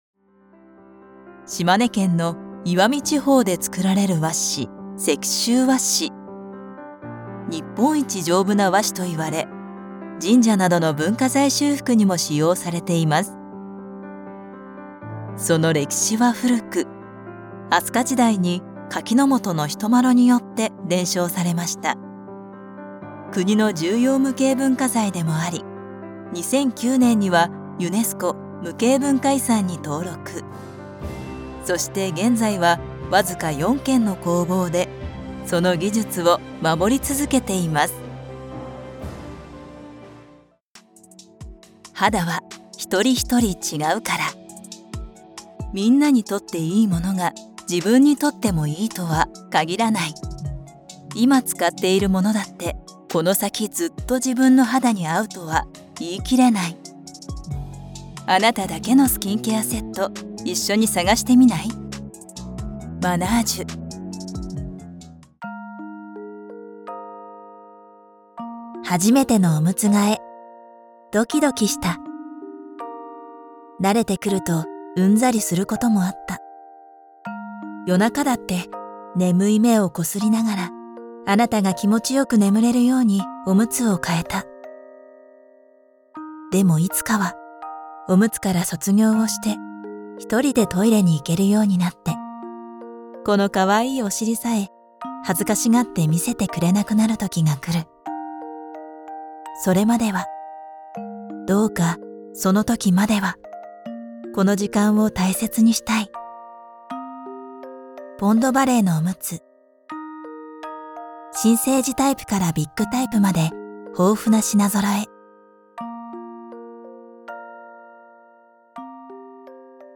軽快・重厚・ナチュラル・上品・ドラマチック・お笑い・キャラクター。柔らかな中低音が七変化。
ボイスサンプル
• 【低音3種】音声ガイド・大人しっとり・ナチュラル